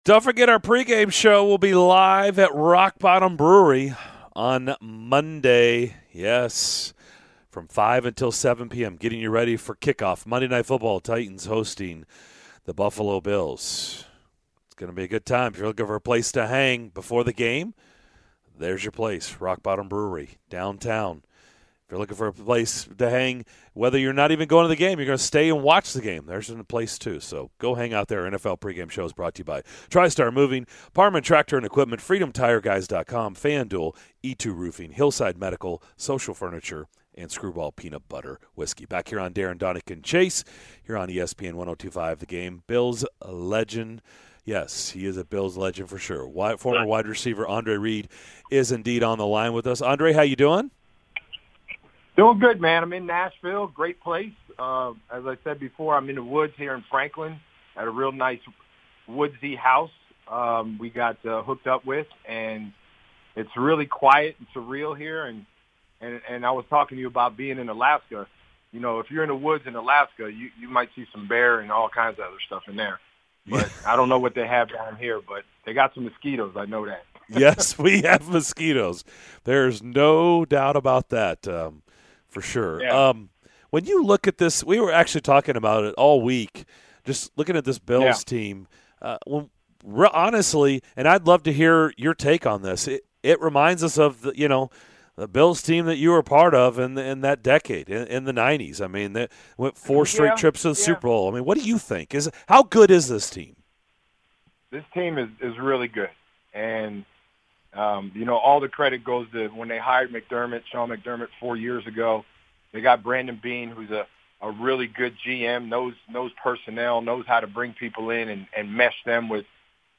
Legendary Bills WR Andre Reed joined the DDC to discuss the upcoming showdown between the Bills and Titans!